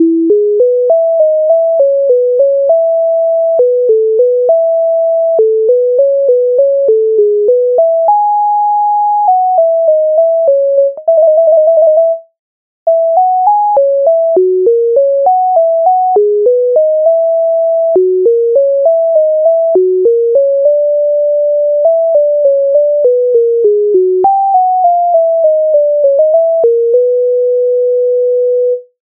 MIDI файл завантажено в тональності E-dur